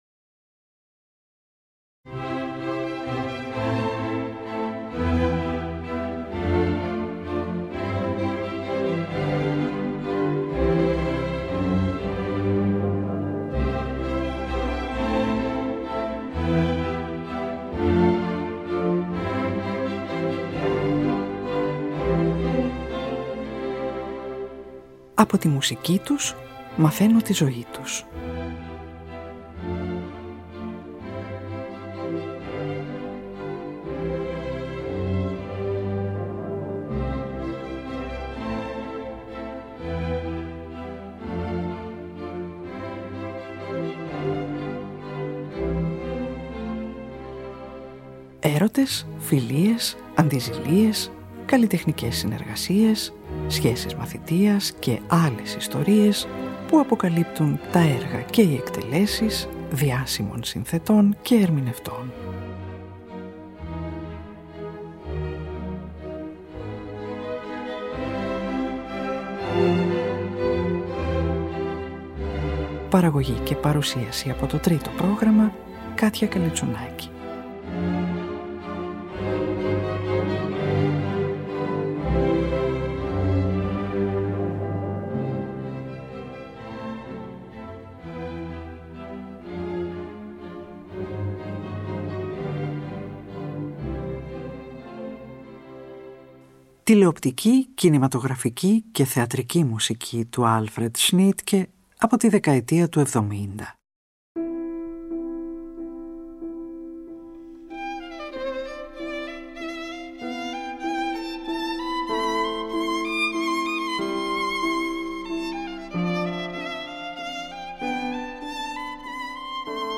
Από τις πρώτες εργασίες του τη δεκαετία του ‘70 ακούγονται 4 αποσπάσματα για ορχήστρα και χορωδία